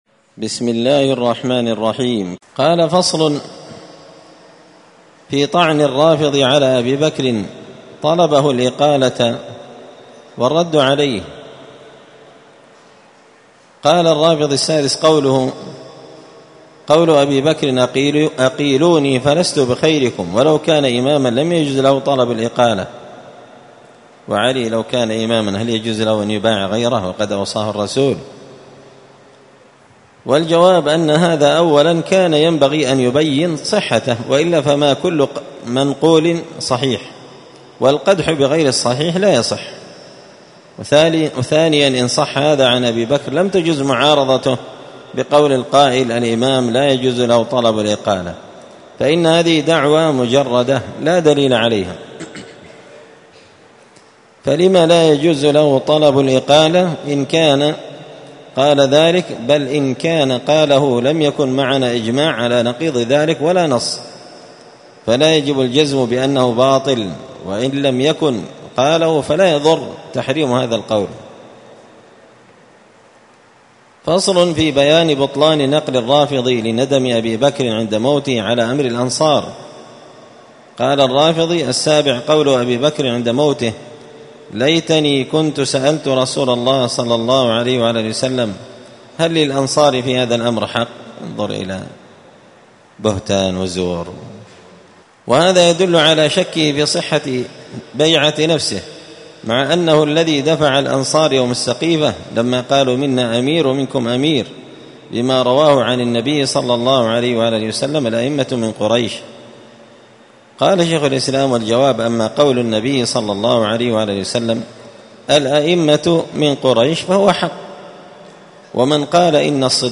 الأربعاء 12 ربيع الأول 1445 هــــ | الدروس، دروس الردود، مختصر منهاج السنة النبوية لشيخ الإسلام ابن تيمية | شارك بتعليقك | 69 المشاهدات
مسجد الفرقان قشن_المهرة_اليمن